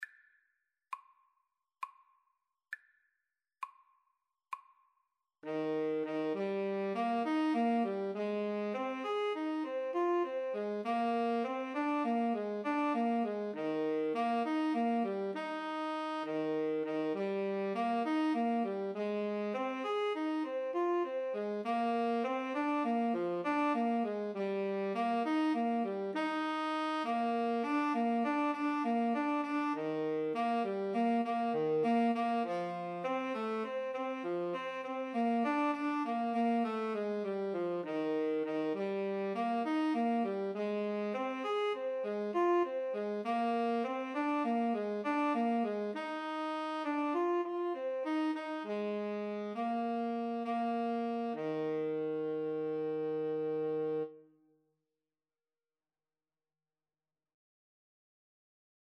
Alto SaxophoneTenor Saxophone
Moderato